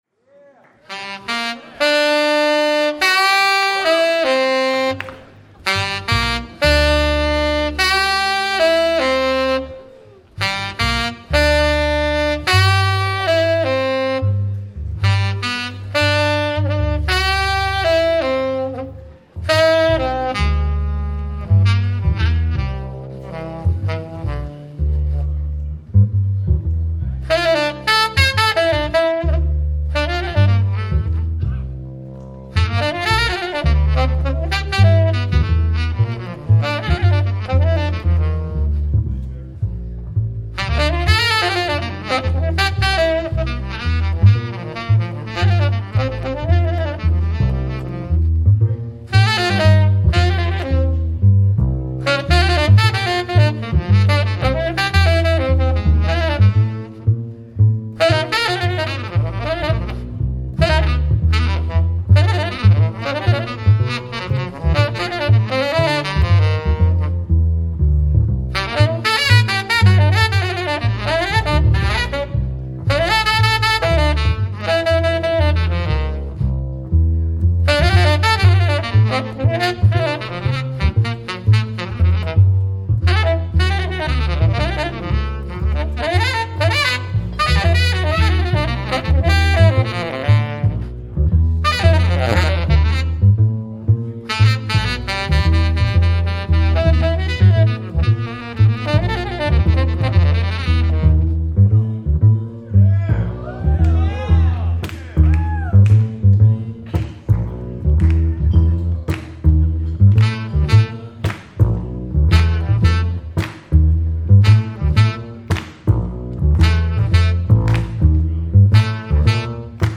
Recorded live at the Vision Festival, NYC